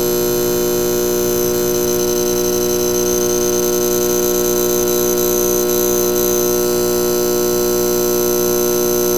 Звуки ветра. Sounds of wind.